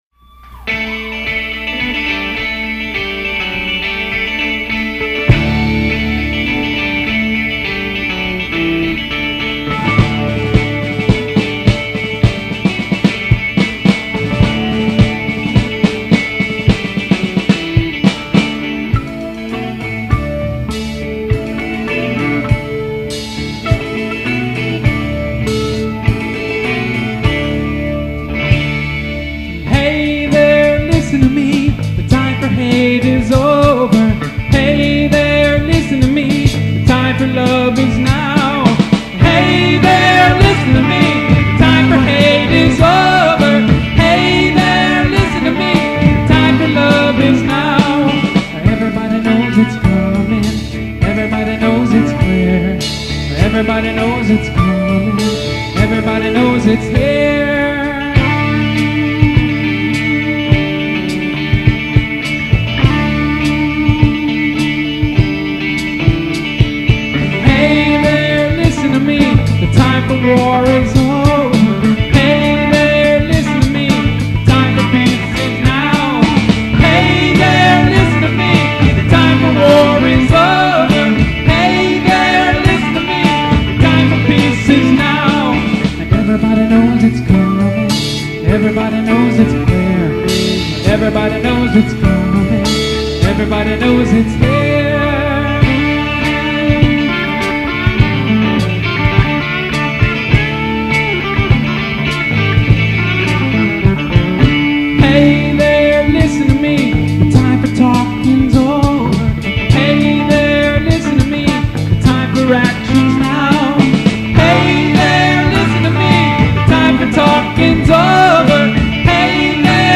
Live with Band